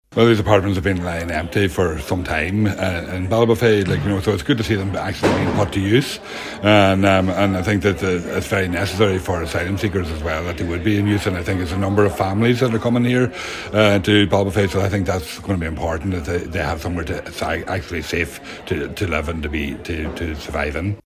Donegal Deputy Thomas Pringle says it’s a welcome development: